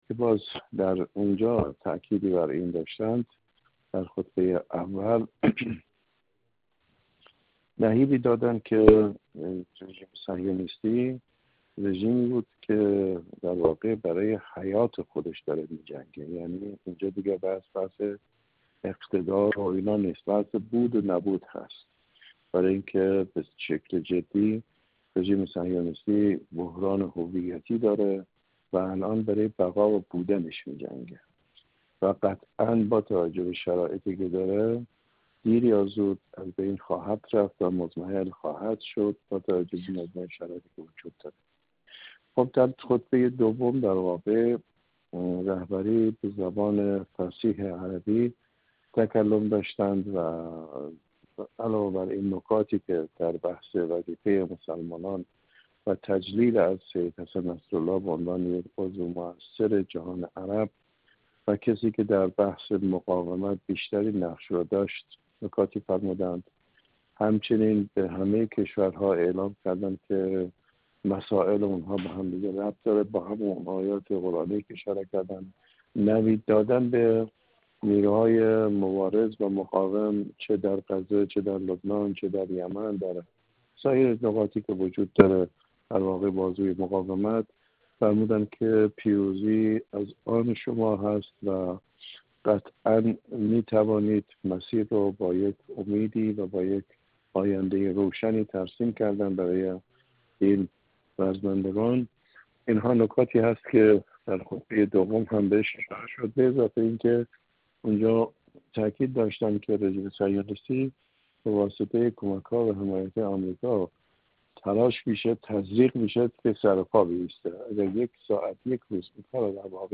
حجت‌الاسلام والمسلمین وحید احمدی، عضو کمیسیون امنیت ملی و سیاست خارجی مجلس
گفت‌وگو